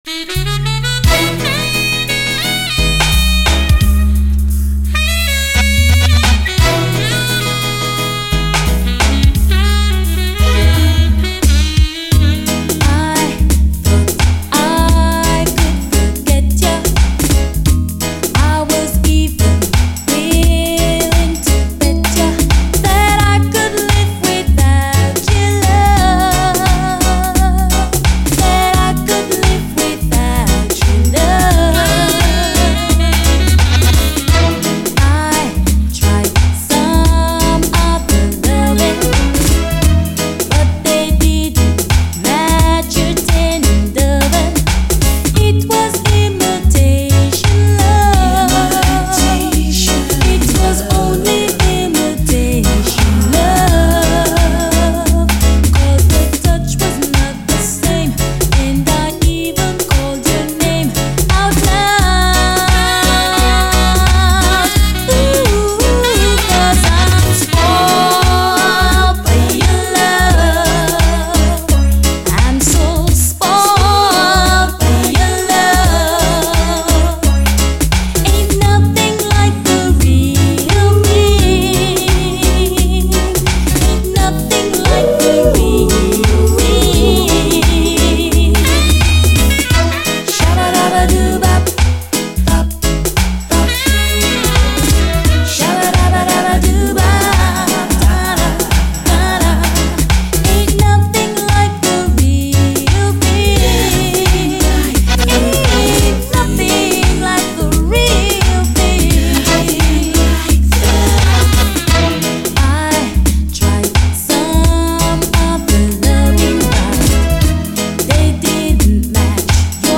REGGAE
UKラヴァーズ・カヴァー！